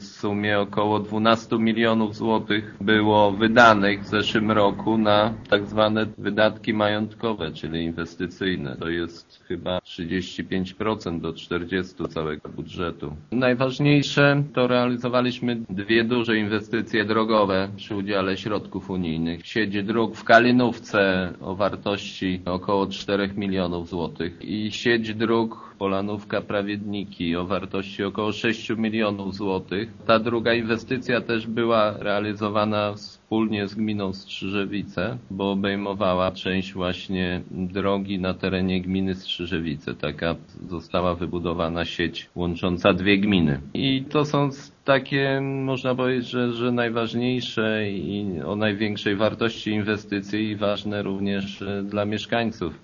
Zdaniem wójta Anasiewicza rok 2010 był dla gminy dobrym okresem. „Podobnie jak inne samorządy mogliśmy skorzystać z wielu funduszy unijnych, które przeznaczyliśmy na inwestycje. W ubiegłym roku wydaliśmy na ten cel kilkanaście milionów złotych” – mówi Jacek Anasiewicz: